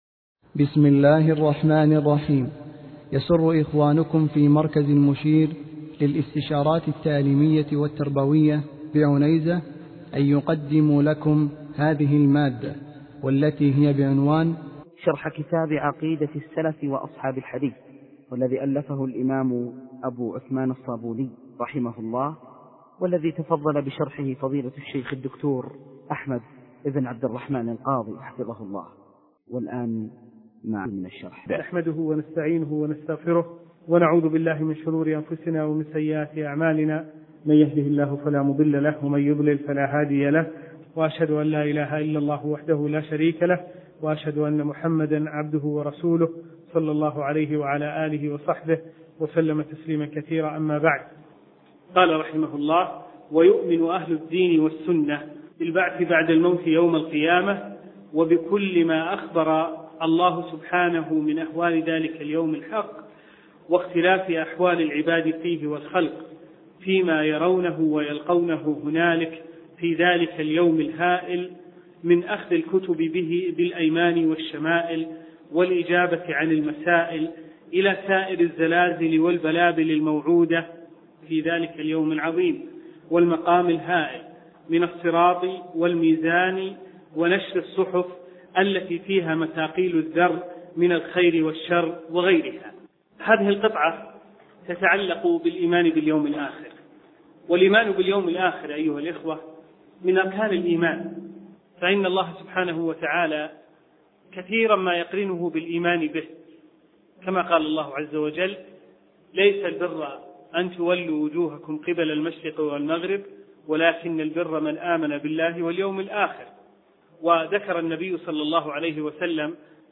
درس : (13) : الإيمان بالبعث بعد الموت.